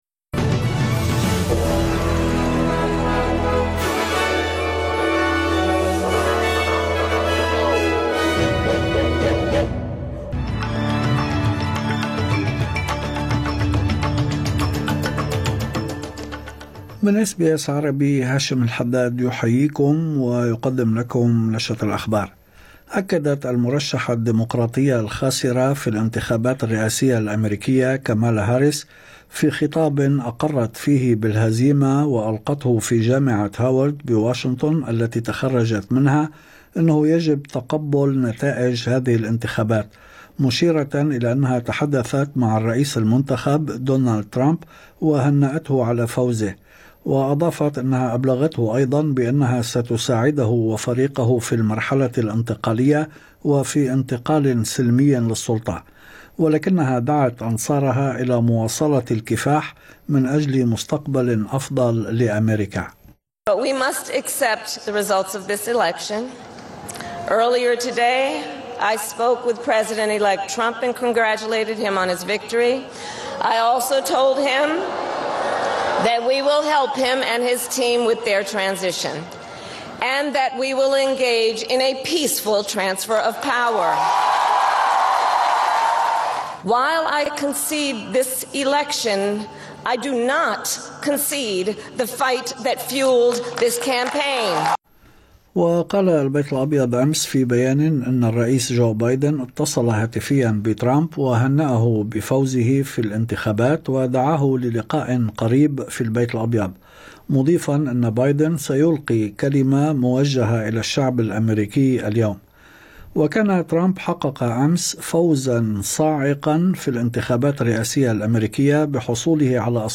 نشرة أخبار الظهيرة 7/11/2024